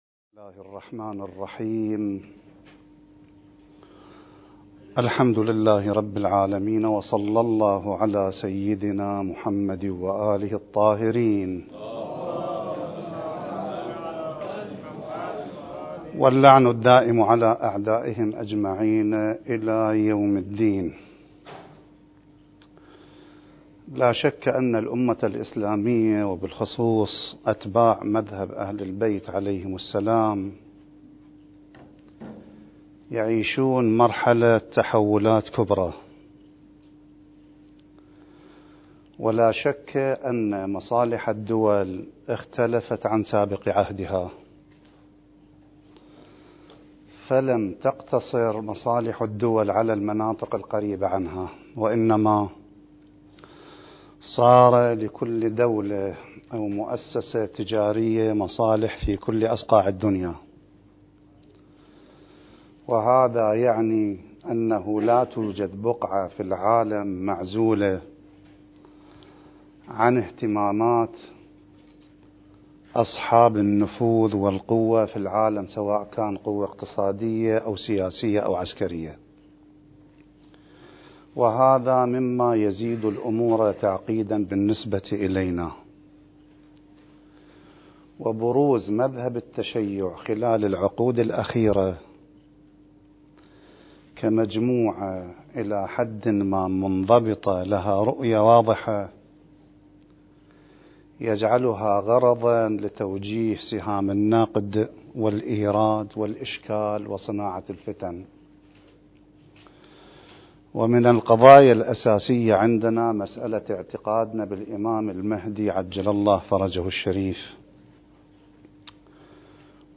المكان: مؤسسة الإمام الحسن المجتبى (عليه السلام) - النجف الأشرف دورة منهجية في القضايا المهدوية (رد على أدعياء المهدوية) (11) التاريخ: 1443 للهجرة